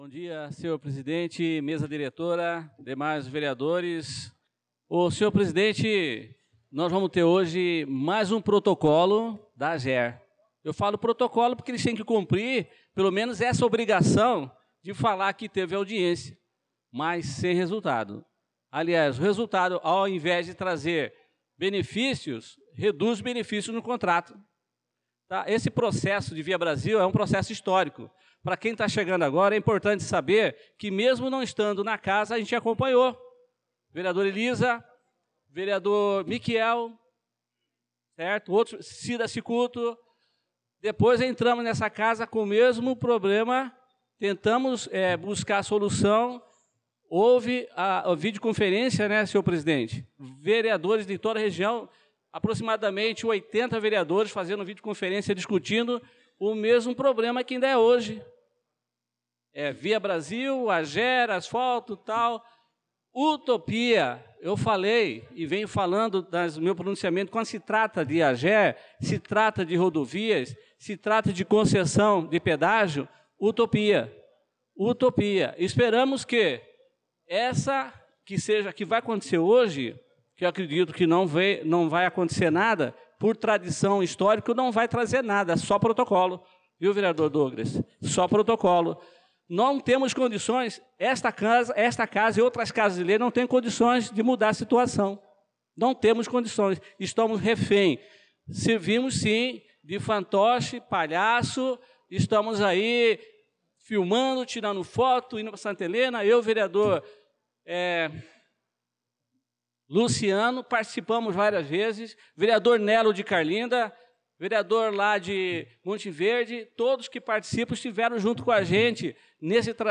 Pronunciamento do vereador Adelson Servidor na Sessão Ordinária do dia 18/03/2025